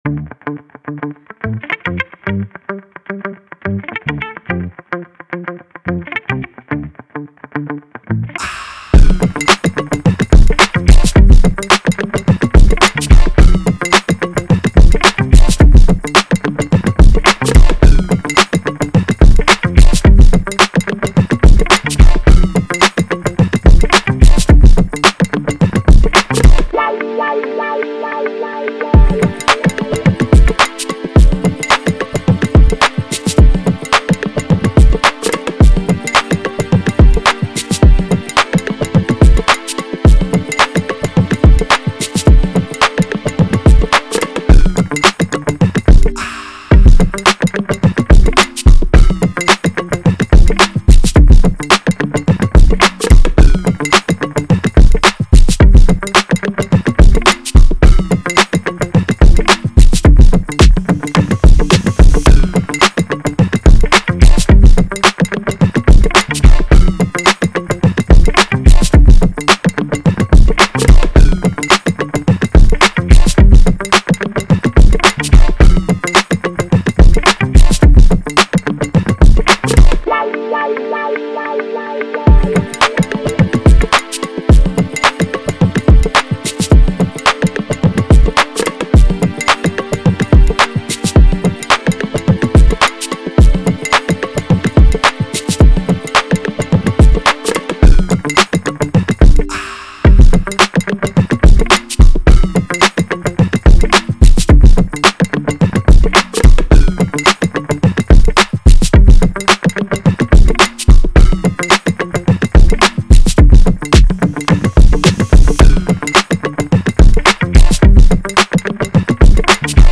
Dmin 108